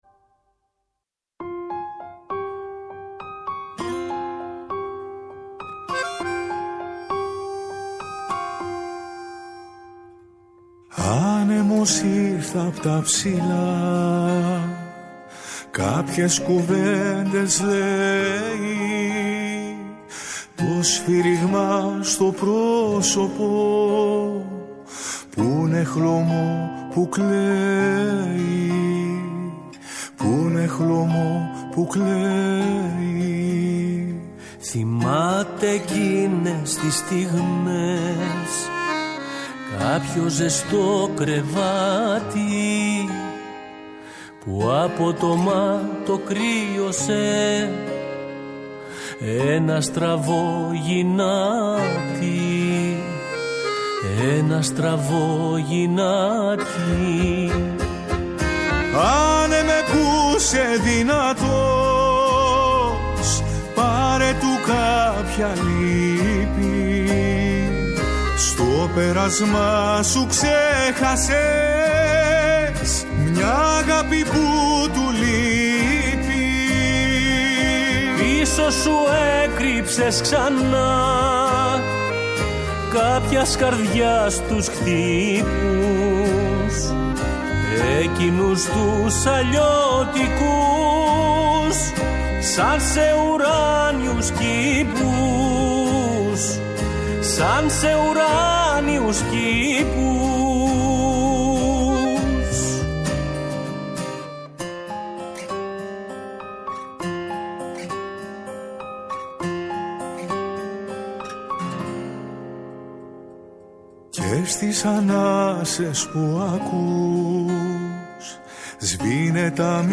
Στην εκπομπή μίλησε για την πορεία του, για την ξενιτιά καθώς και για την ποίηση ενώ μεταδόθηκαν και μελοποιημένα ποιήματά του. Η ΦΩΝΗ ΤΗΣ ΕΛΛΑΔΑΣ